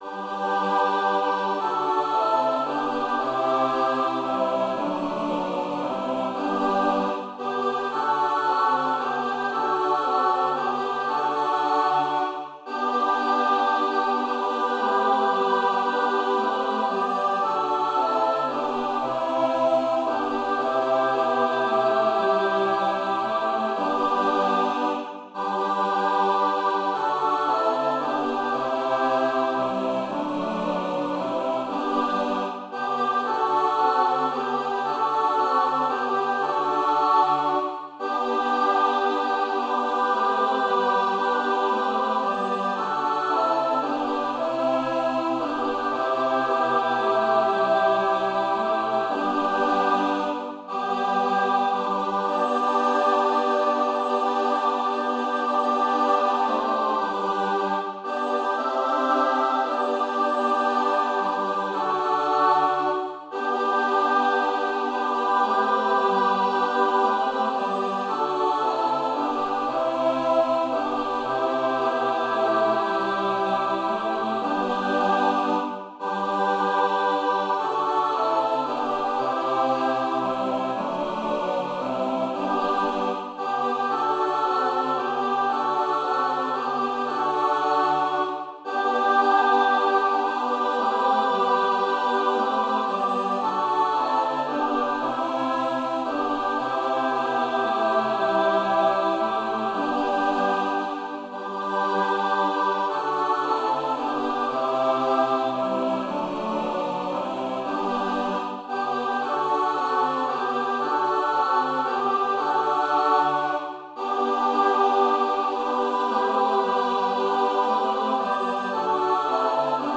Voicing/Instrumentation: SATB